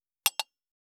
264,ショットグラス乾杯,乾杯,アルコール,バー,お洒落,モダン,カクテルグラス,ショットグラス,おちょこ,テキーラ,シャンパングラス,カチン,チン,カン,ゴクゴク,プハー,シュワシュワ,コポコポ,ドボドボ,トクトク,カラカラ,
コップ